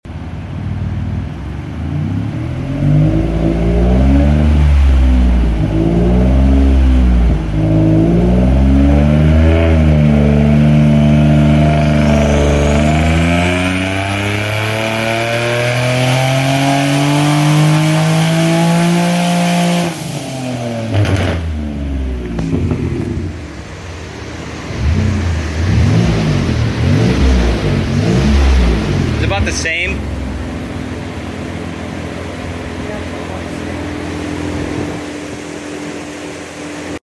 515 WHP Genesis Coupe 3.8T sound effects free download
515 WHP Genesis Coupe 3.8T w/ Stock Block! Remnant Performance Turbo Kit & Alphaspeed Tuned!